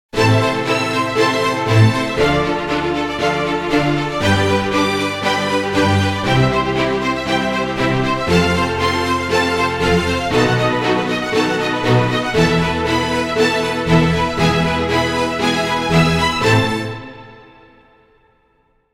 Genre: filmscore, logo, production.